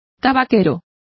Complete with pronunciation of the translation of tobacconists.